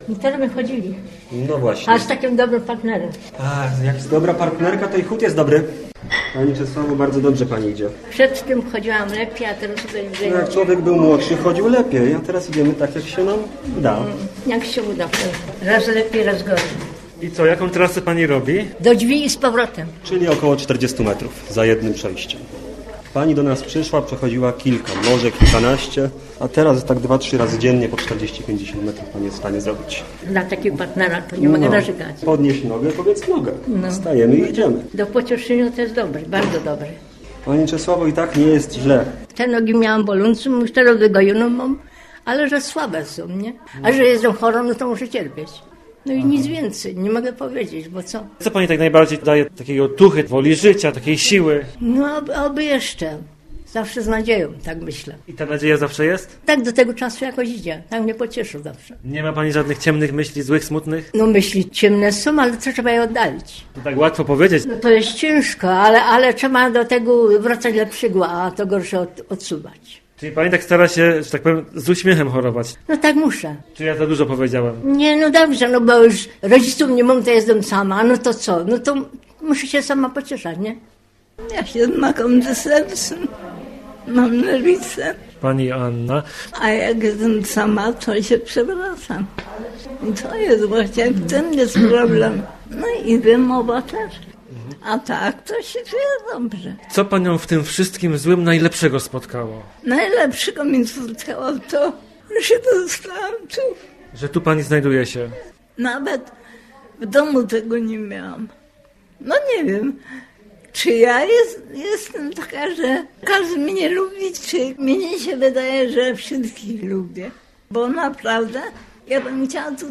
11 lutego przypadał Światowy Dzień Chorego ustanowiony przez papieża Jana Pawła II. Nasz reporter pojechał do Jarosławca w gminie Środa Wielkopolska i w Zakładzie Opiekuńczo-Pielęgnacyjnym porozmawiał z chorymi - o tym, co przynosi ulgę w chorobie i czy można chorować godnie.